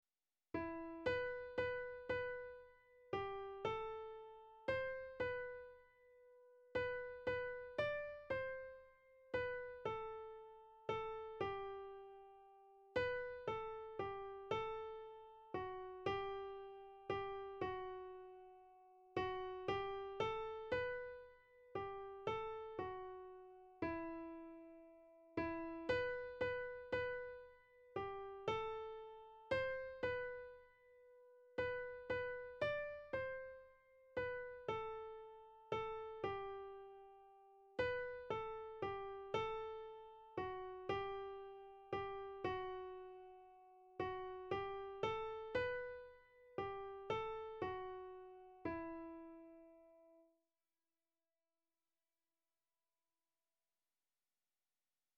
Répétition de la pièce musicale N
Soprane
Par la musique et par nos voix_soprano copie.mp3